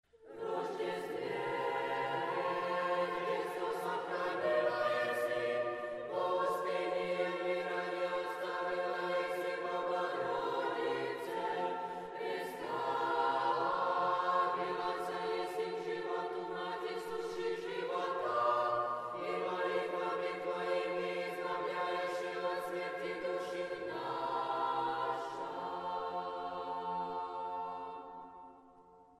“B” Liturgia – négyszólamú ünnepi dallamokkal (mp3)